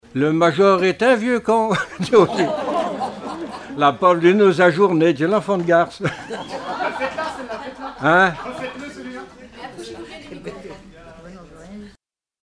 Chants brefs - Conscription
Répertoire de chansons populaires et traditionnelles
Pièce musicale inédite